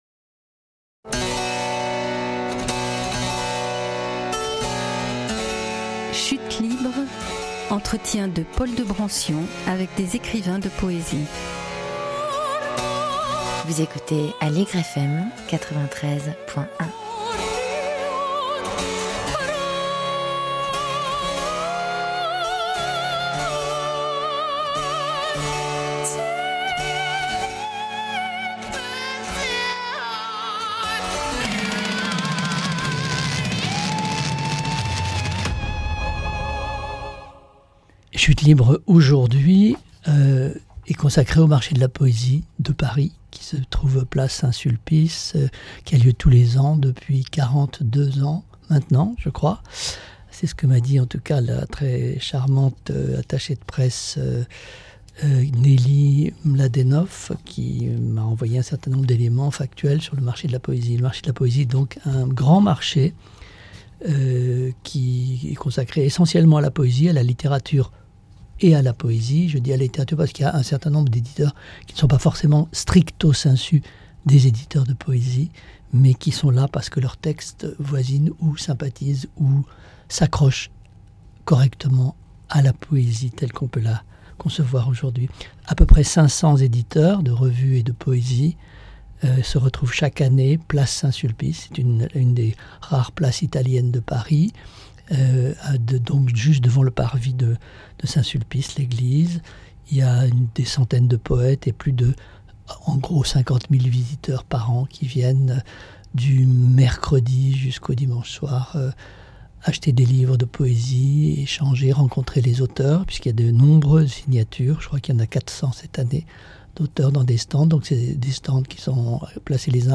À l'occasion du Marché de la poésie 2025